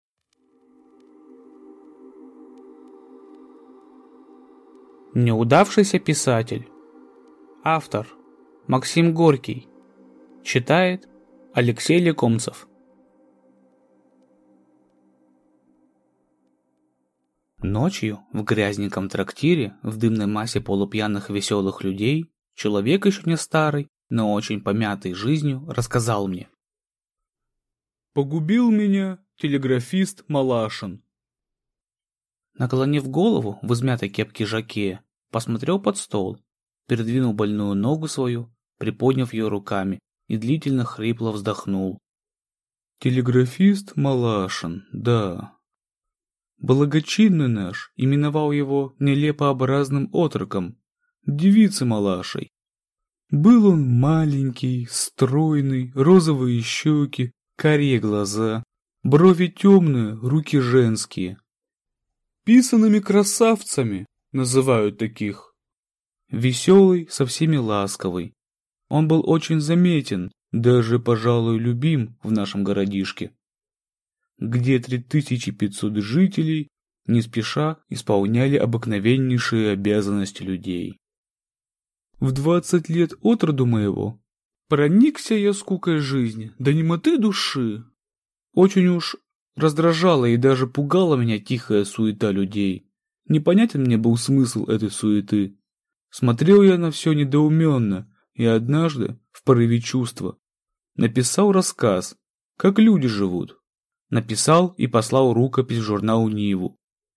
Аудиокнига Неудавшийся писатель | Библиотека аудиокниг